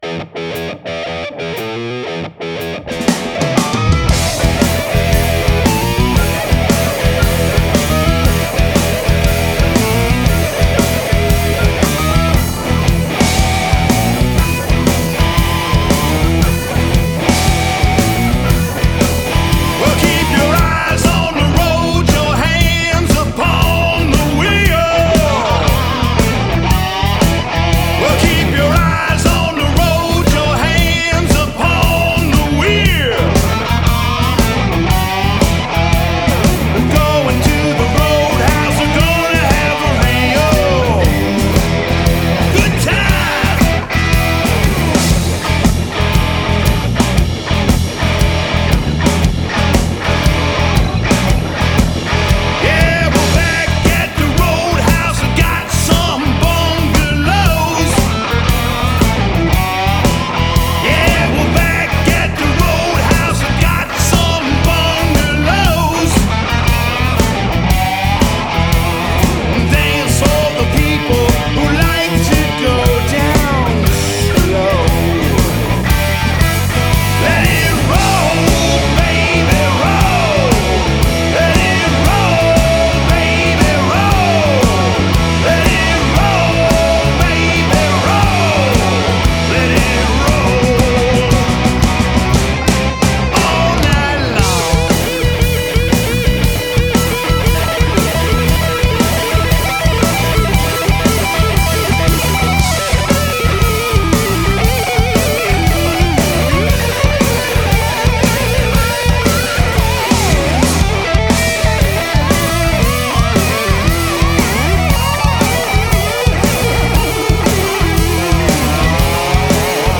Тип альбома: Студийный
Жанр: Blues-Rock